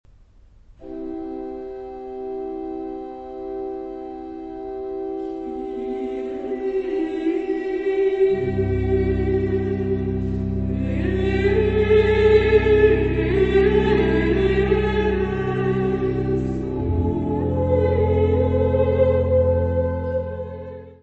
Music Category/Genre:  Classical Music
for sopranos, mezzos, altos and organ